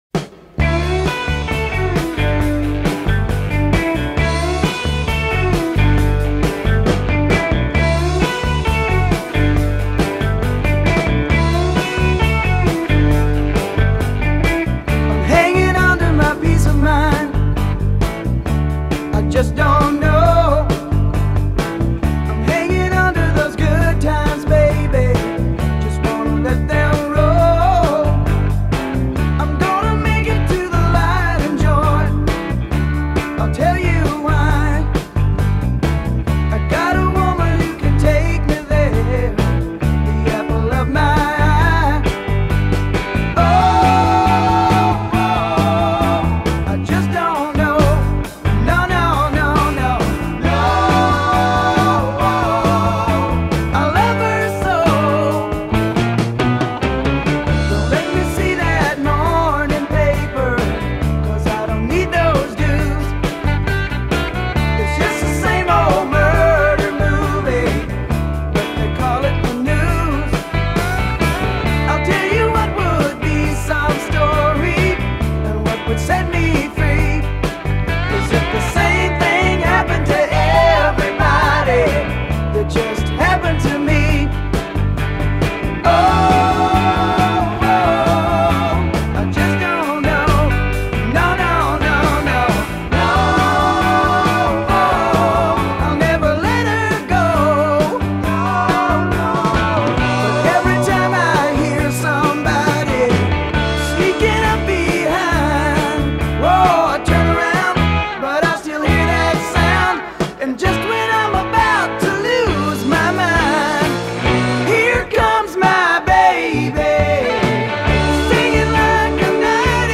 Vocal and Bass player.